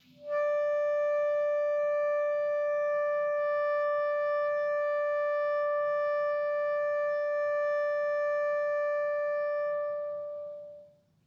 Clarinet
DCClar_susLong_D4_v1_rr1_sum.wav